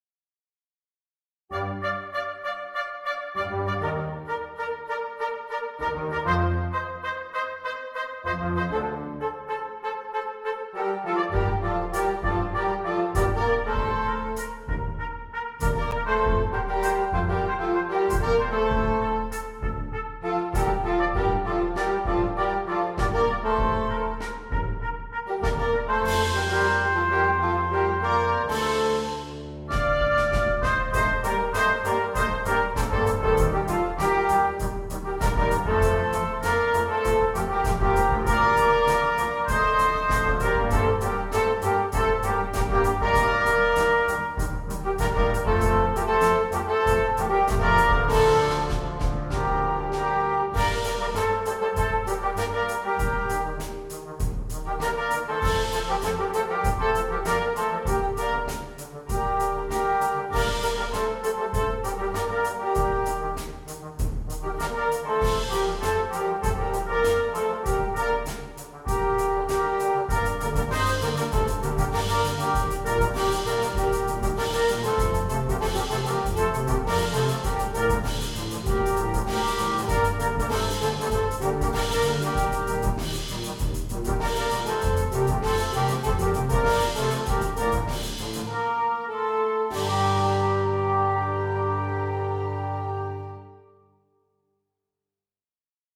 Virtual recordings were made using NotePerfomer 3.